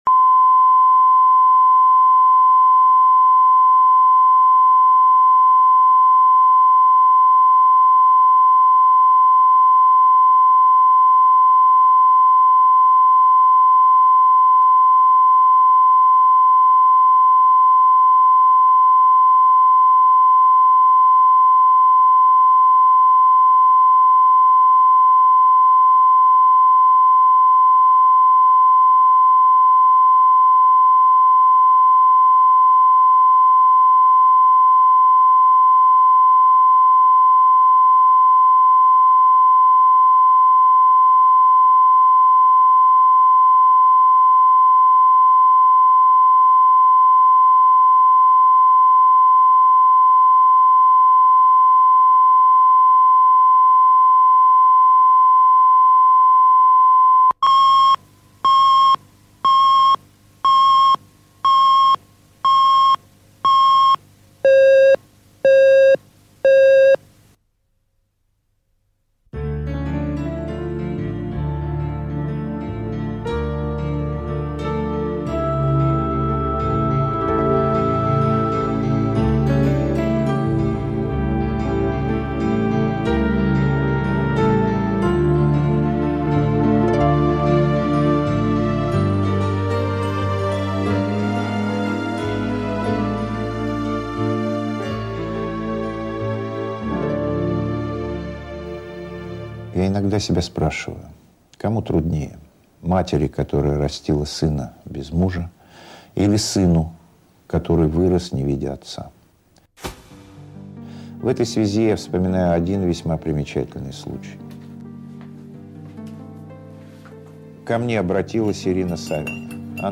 Аудиокнига Вне зоны доступа | Библиотека аудиокниг